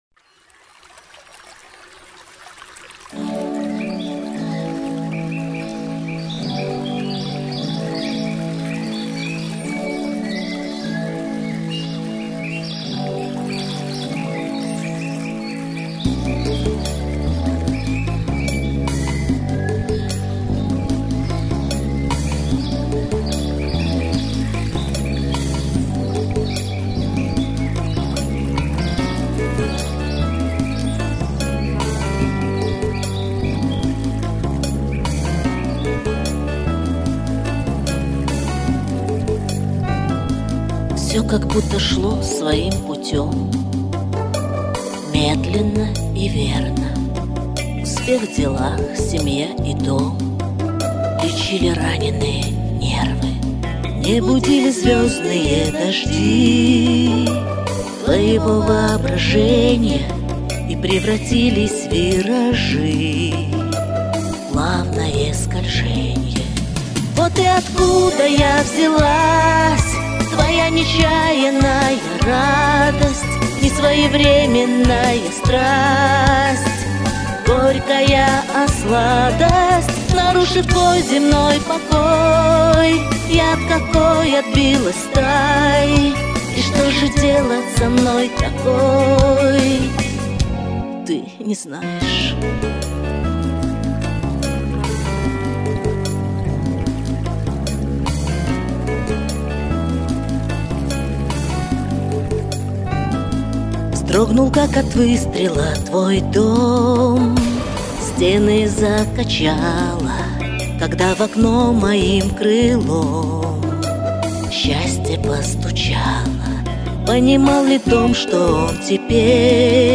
Комментарий соперника: Женский вариант.